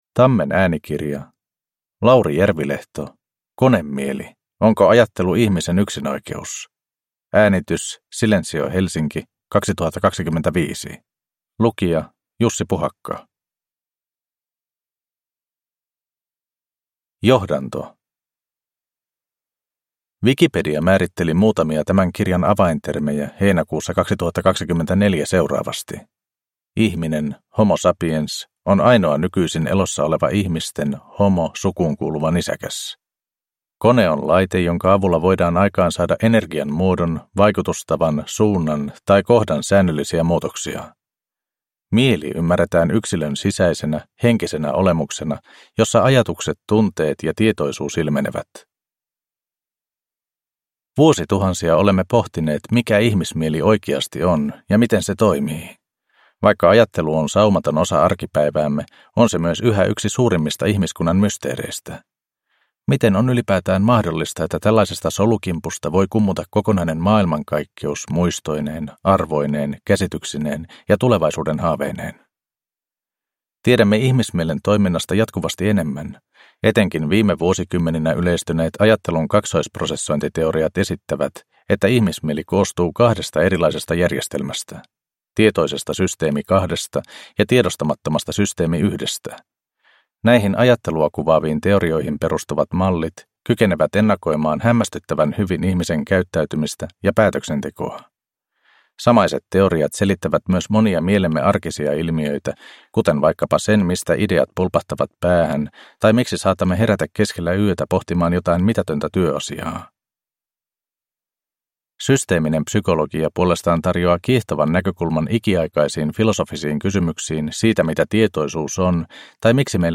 Konemieli – Ljudbok